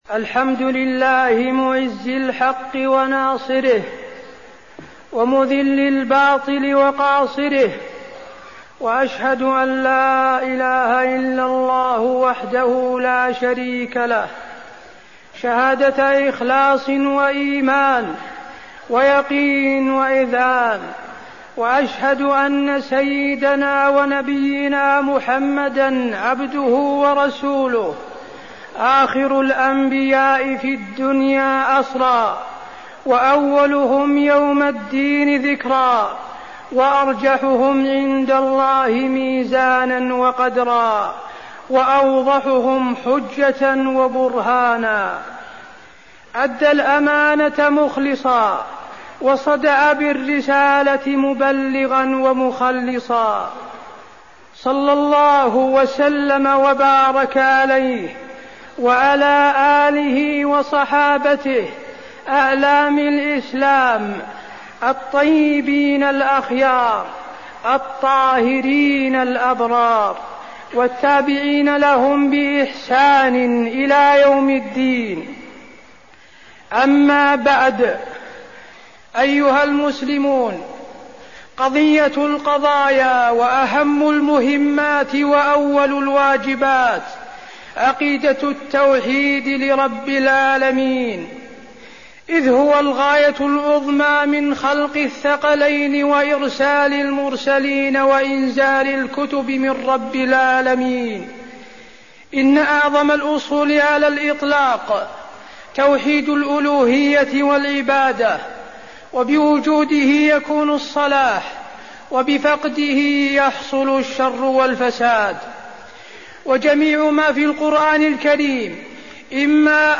تاريخ النشر ٢٣ شوال ١٤١٨ هـ المكان: المسجد النبوي الشيخ: فضيلة الشيخ د. حسين بن عبدالعزيز آل الشيخ فضيلة الشيخ د. حسين بن عبدالعزيز آل الشيخ الأمن يكون بالعقيدة الصحيحة